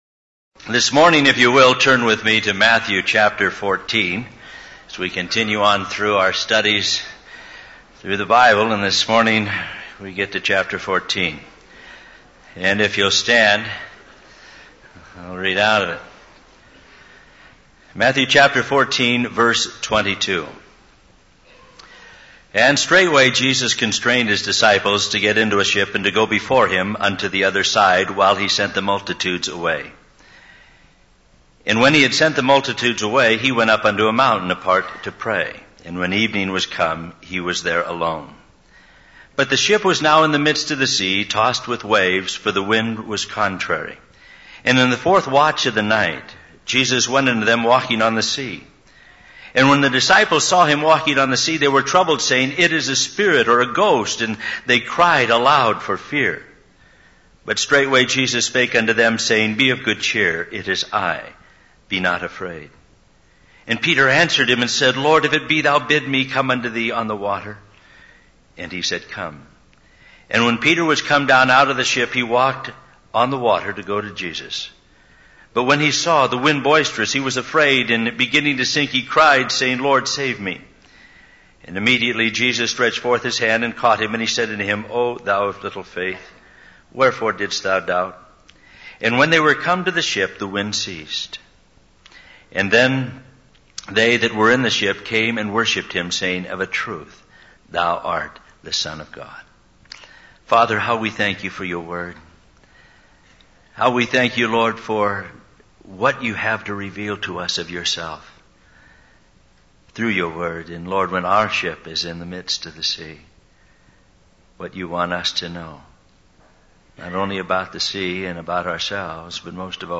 In this sermon, the speaker discusses the story of Jesus sending his disciples into a stormy situation on the Sea of Galilee.